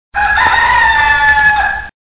Gallo chicchirichi
Classico verso del gallo.
rooster.mp3